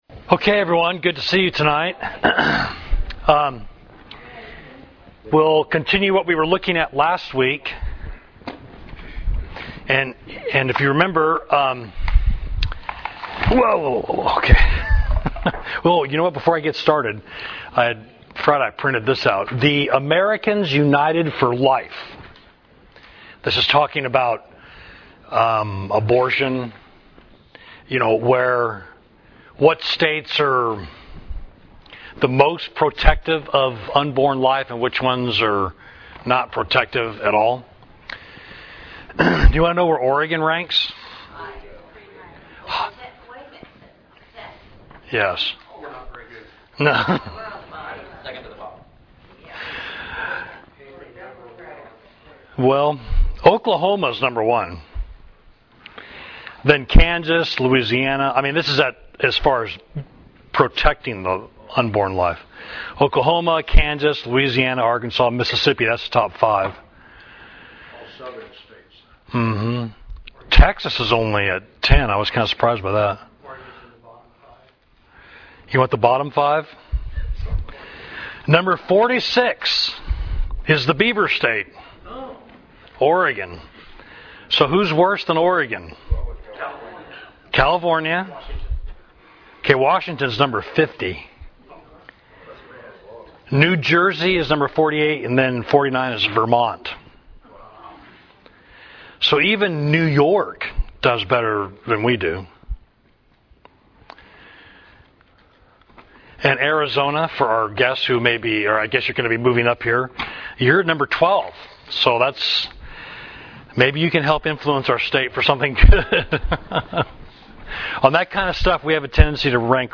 Class: How Would You Answer This Atheist?